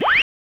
psychobeep.wav